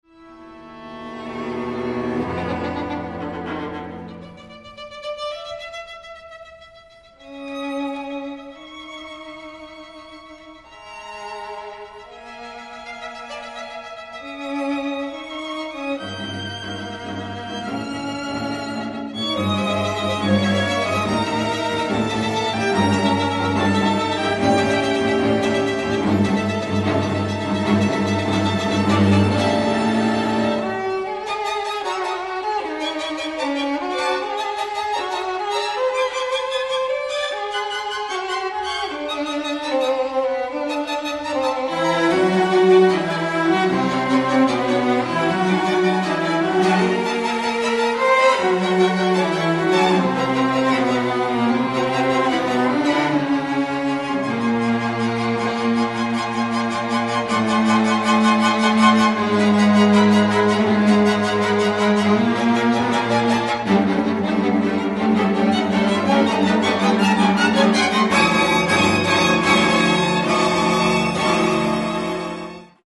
string octet